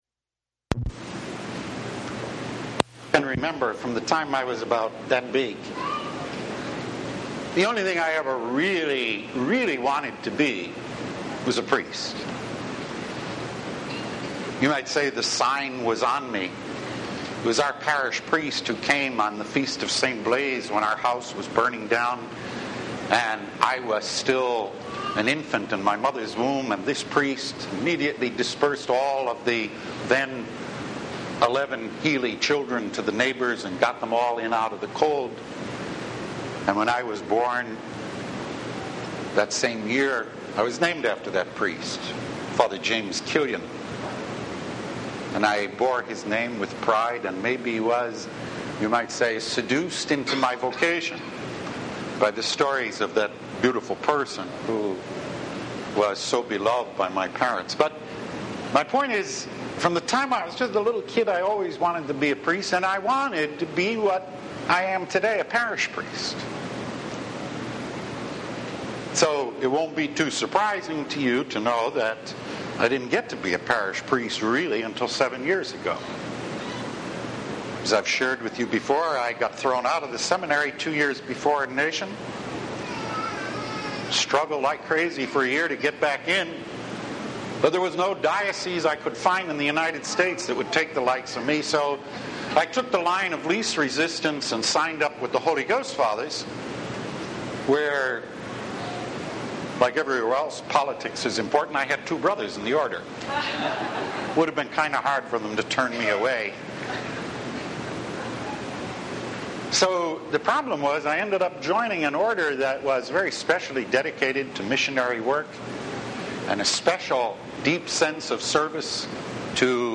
Priesthood – Weekly Homilies